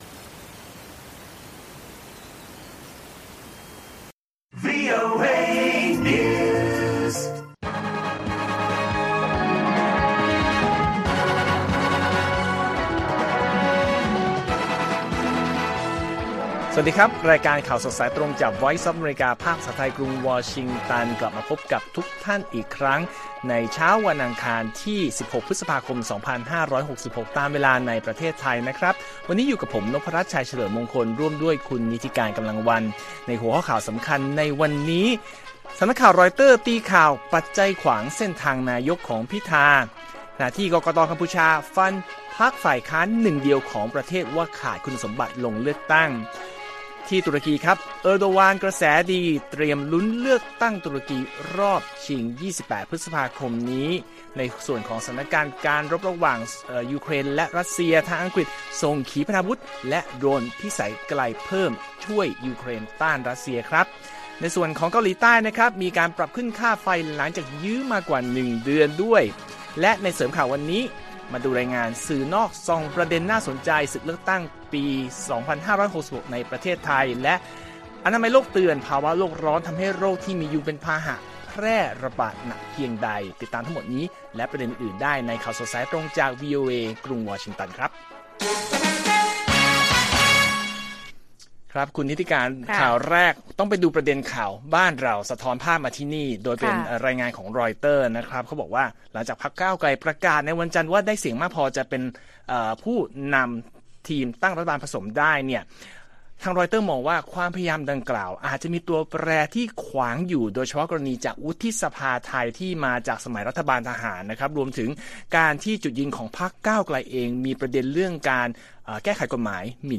ข่าวสดสายตรงจากวีโอเอ ไทย อังคาร 16 พ.ค.2566